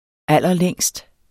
Udtale [ ˈalˀʌˈlεŋˀsd ]